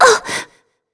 Shea-Vox_Damage_03.wav